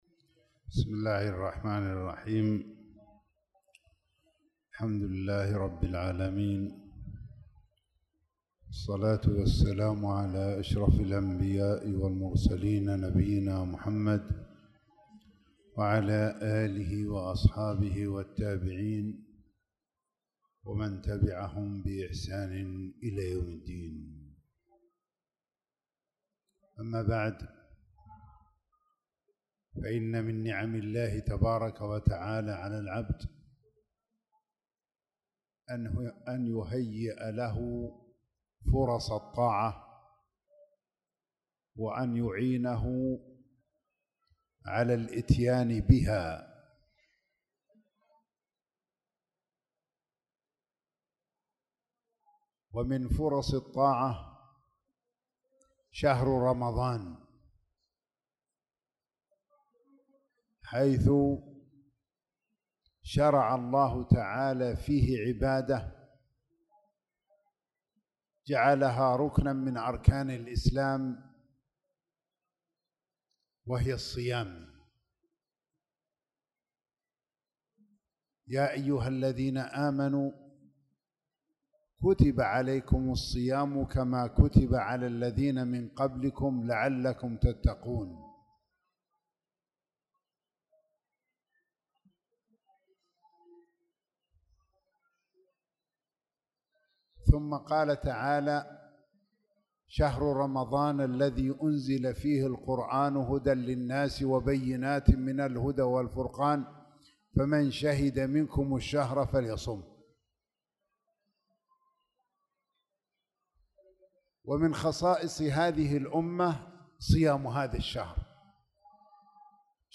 درس عن الصيام
تاريخ النشر ٢٩ شعبان ١٤٣٧ هـ المكان: المسجد الحرام الشيخ